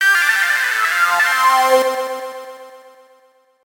メール音やSMSの通知音。
このリズミカルでジャズテイストのある音は、メールやSMSの通知音として使うと、爽やかな気分を与えてくれるでしょう。